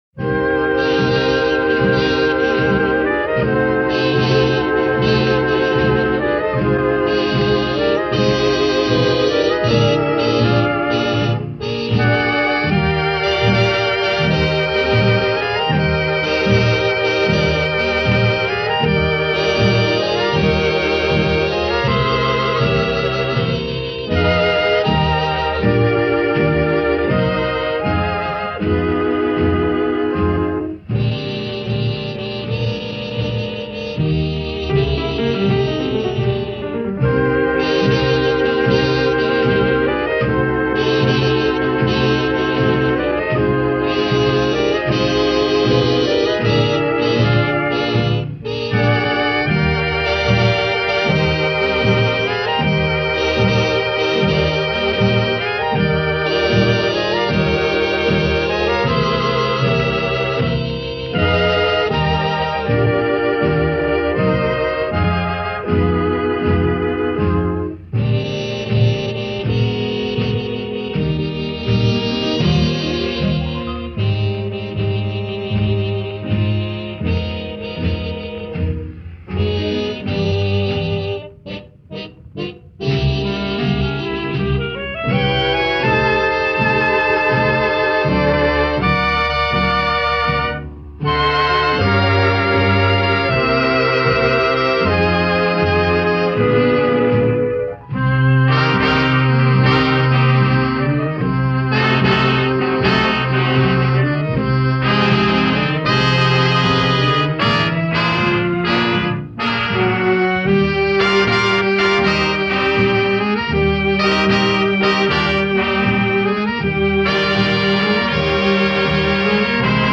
Genre: Traditional Pop, Vocal Jazz, Easy Listening